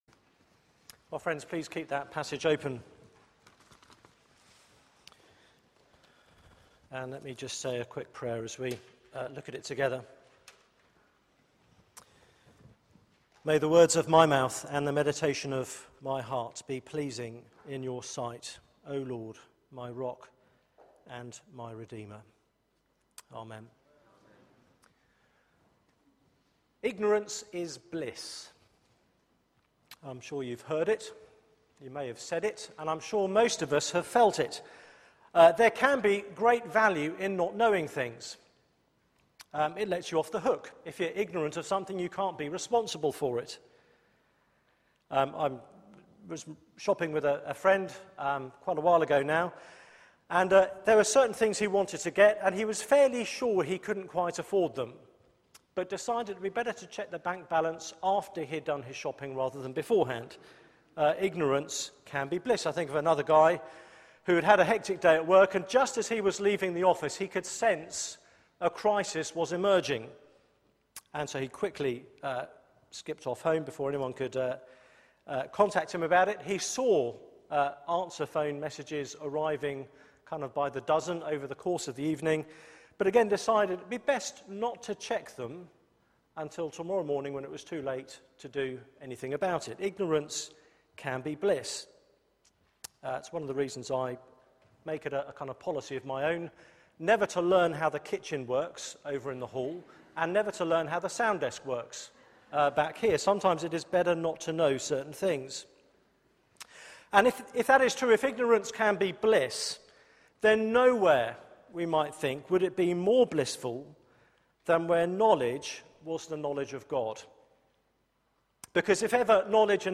Media for 6:30pm Service on Sun 11th Aug 2013
Theme: More precious than gold Sermon (Last few minutes missing)